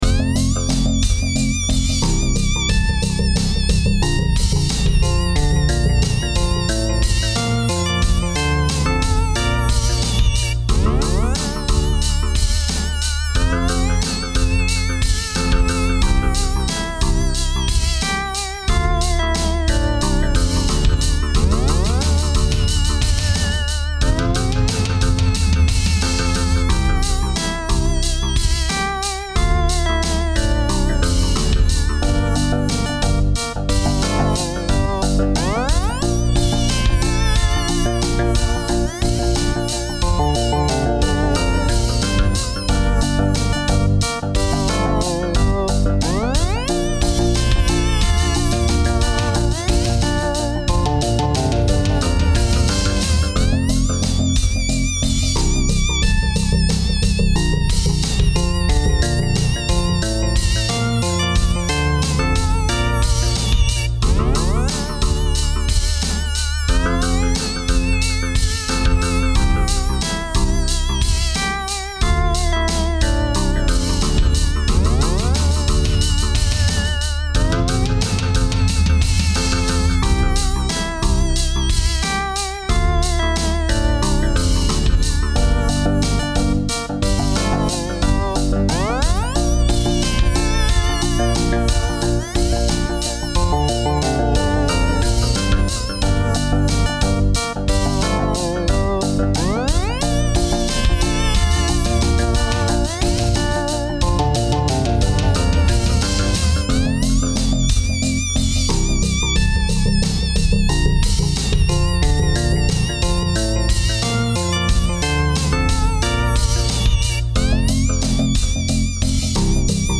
Home > Music > Electronic > Bright > Dreamy > Laid Back